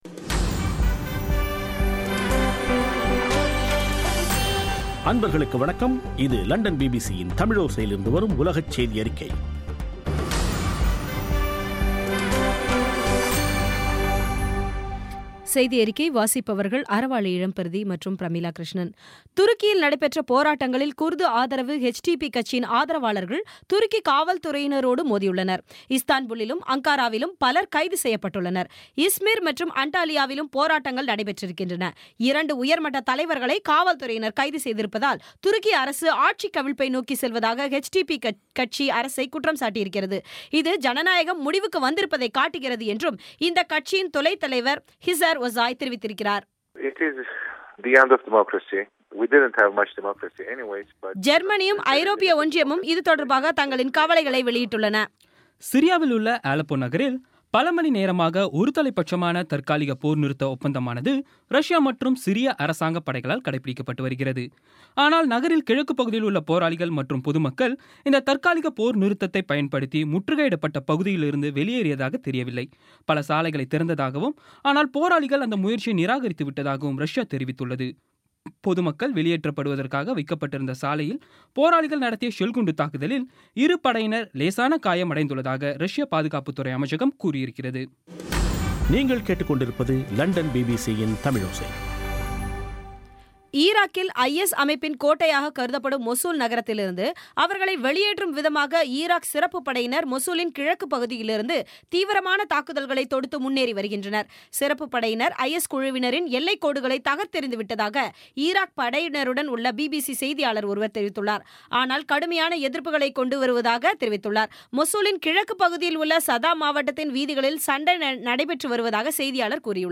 இன்றைய (நவம்பர் 4ம் தேதி ) பிபிசி தமிழோசை செய்தியறிக்கை